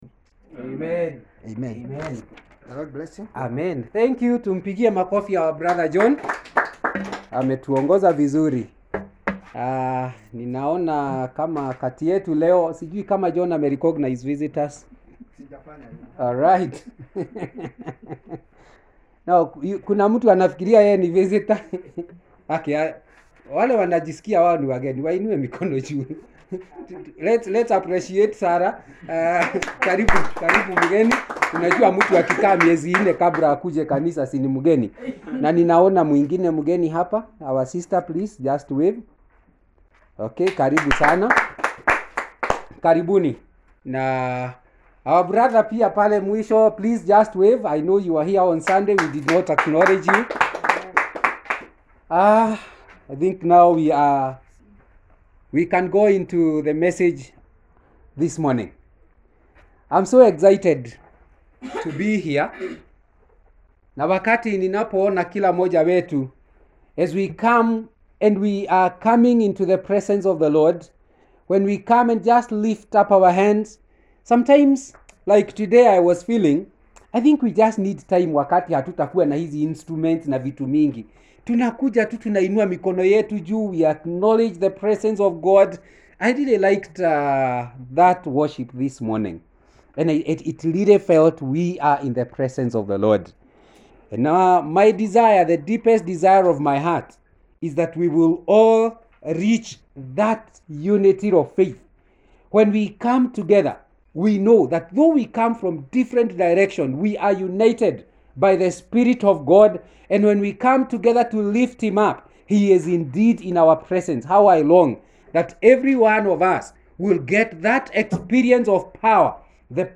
16th August Sermon